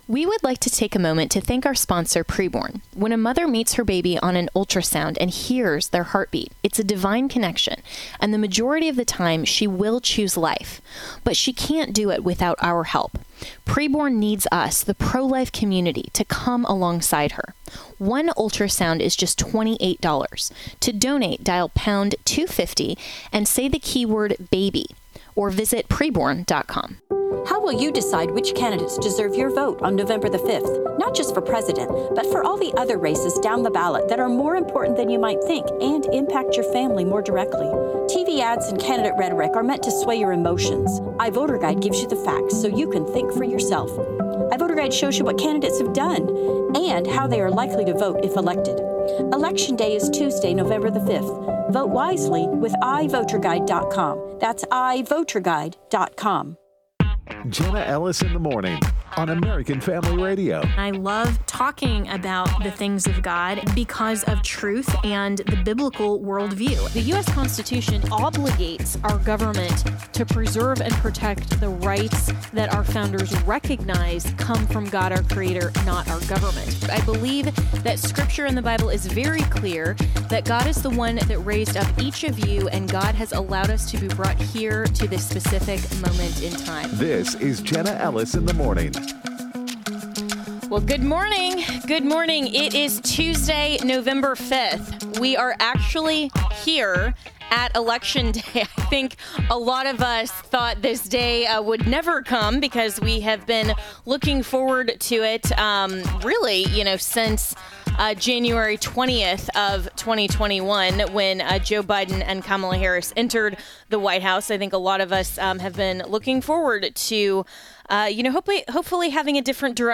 Radio hosts